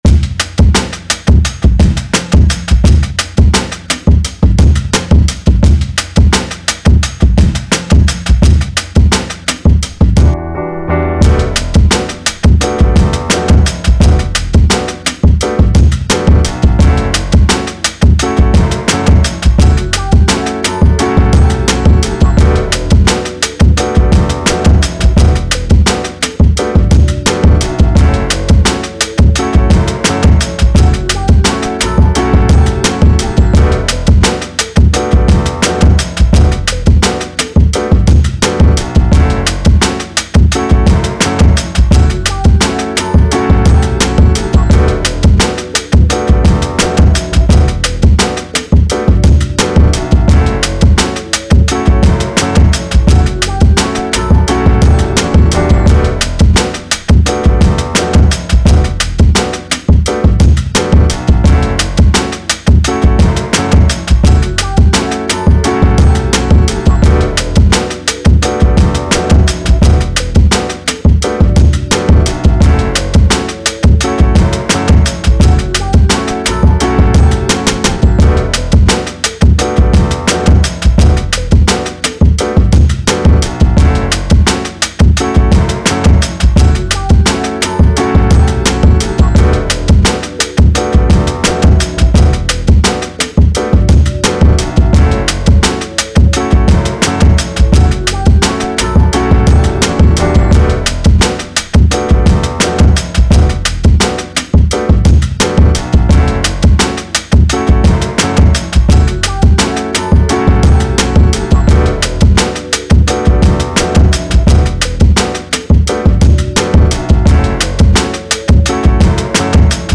Hip Hop, R&B
D Major